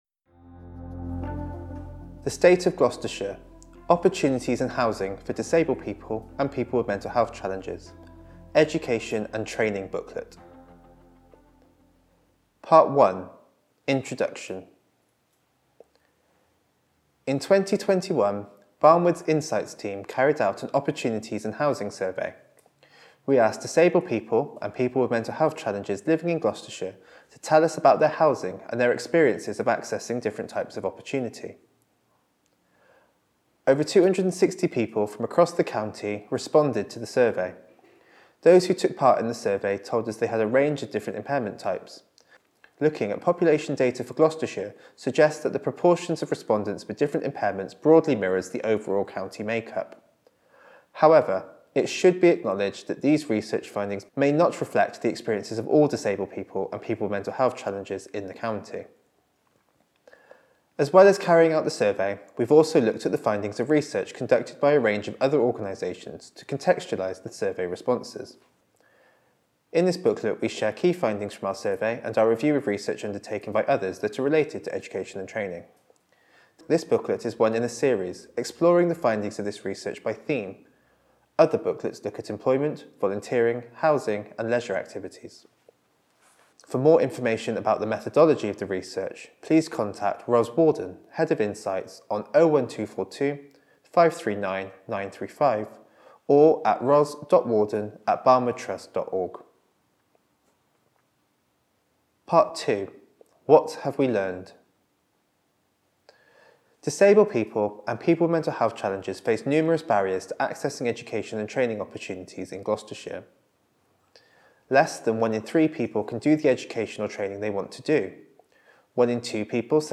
British Sign Language (BSL) translation Subtitled video of the report being read by one of our Researchers Audio recording of the report being read by one of our Researchers Easy Read version to read or download Large print version to read or download